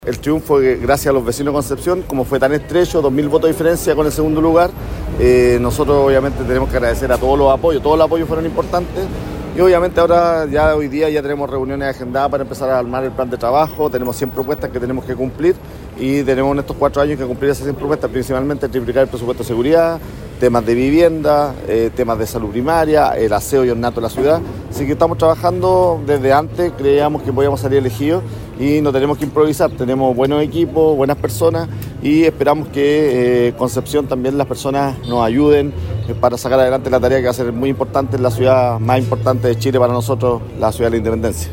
Entre aplausos y felicitaciones llegó a un céntrico café penquista el alcalde electo por la comuna de Concepción, Héctor Muñoz, para dialogar con la prensa en las horas posteriores a su triunfo electoral.